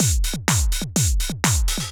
Rotterdam Beat_125.wav